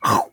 super_foods_crunch.4.ogg